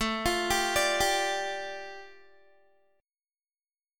Listen to A7sus4#5 strummed